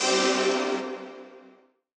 DDW Hit 1.wav